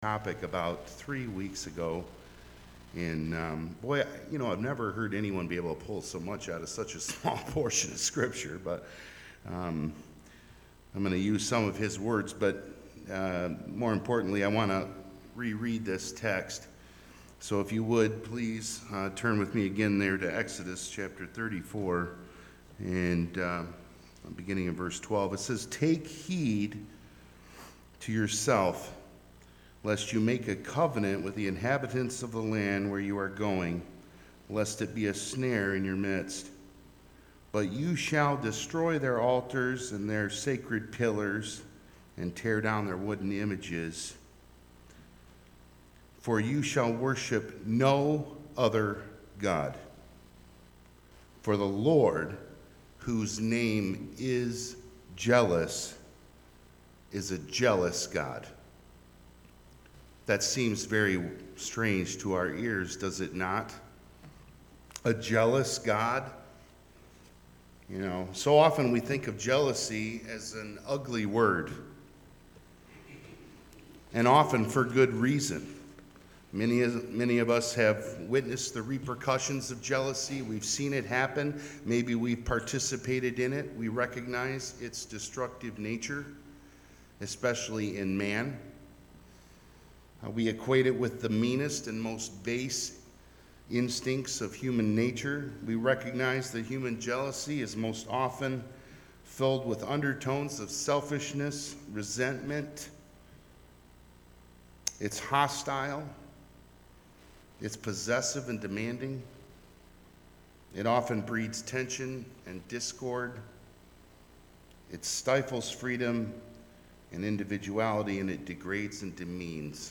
Message
A message from the series "Guest Speakers." Youth Sunday; The Youth Group from Bancroft Congregational Church performed most of the worship this morning.